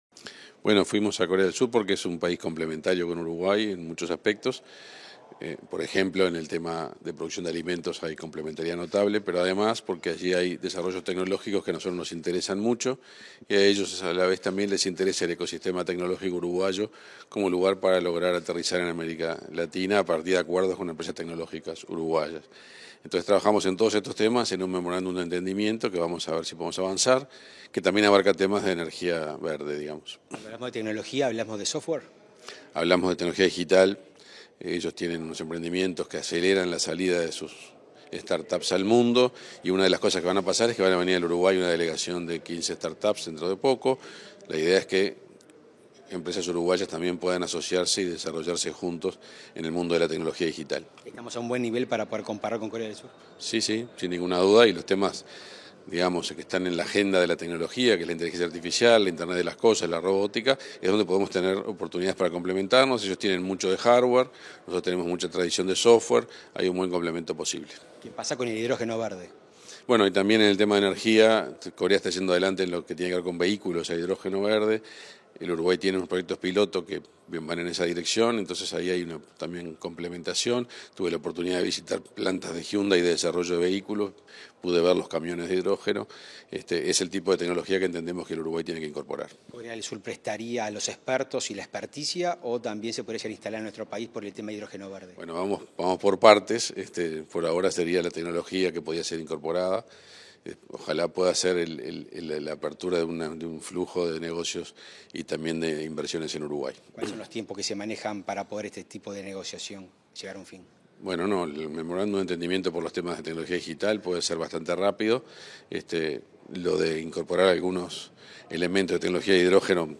Entrevista al ministro de Industria, Energía y Minería, Omar Paganini